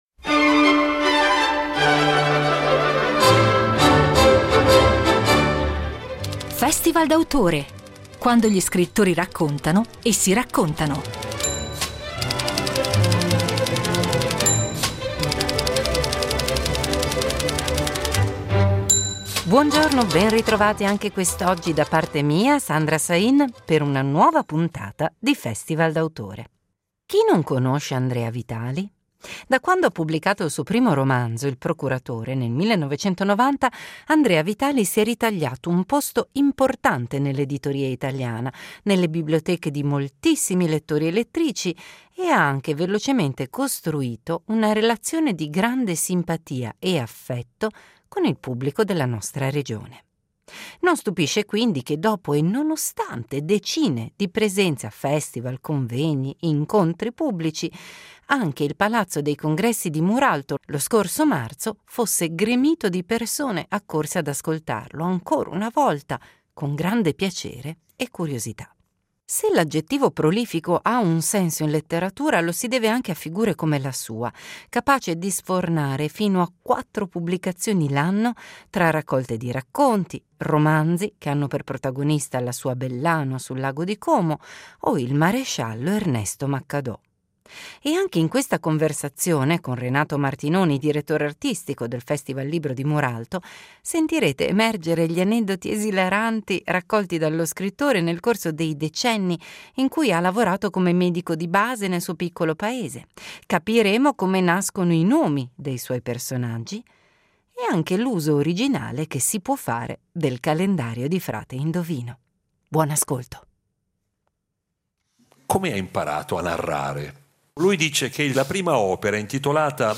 Incontro al Festival del Libro di Muralto